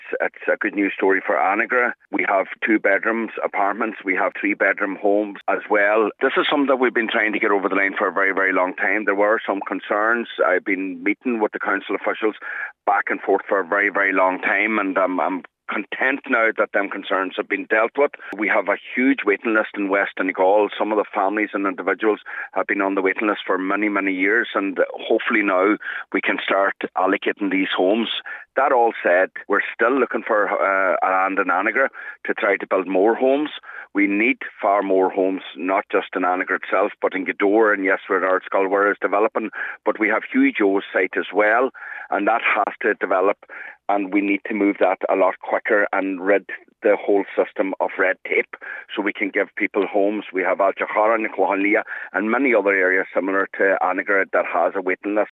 Local Cllr Michael Colm Mac Giolla Easbuig says it’s a significant development…………….